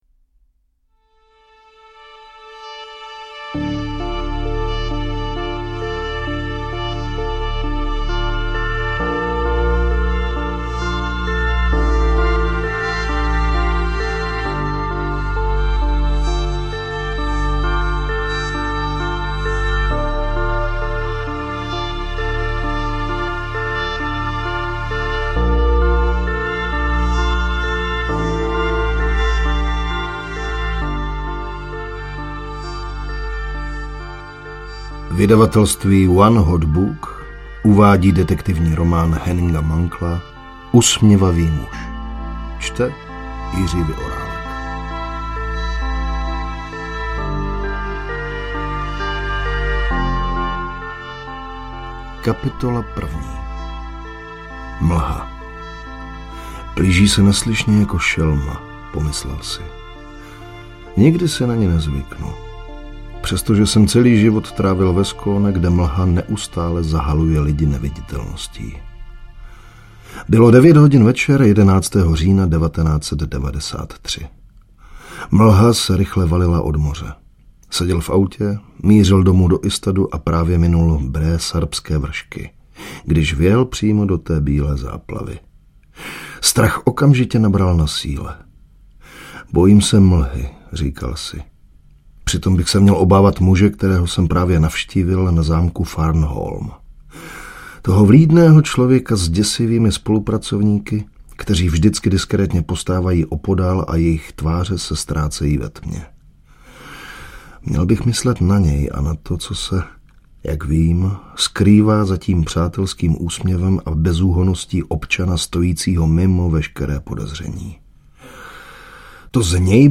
Audiokniha Usměvavý muž, kterou napsal Henning Mankell.
Ukázka z knihy
• InterpretJiří Vyorálek